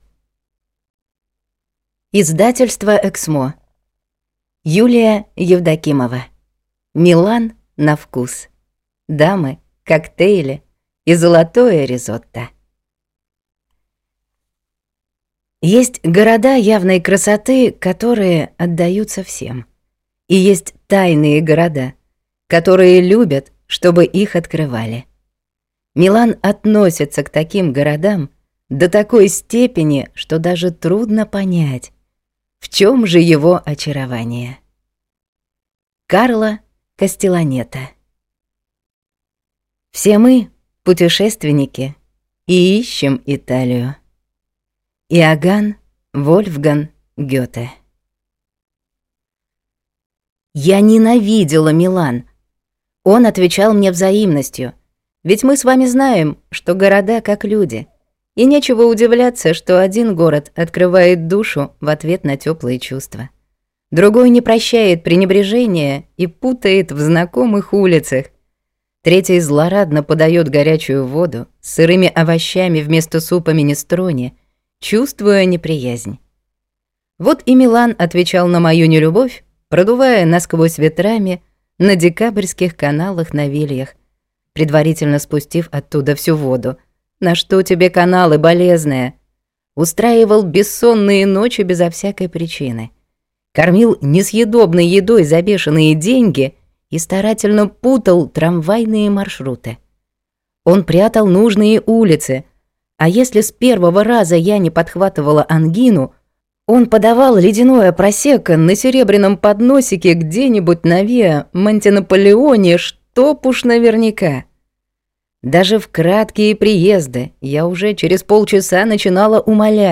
Аудиокнига Милан на вкус. Дамы, коктейли и золотое ризотто | Библиотека аудиокниг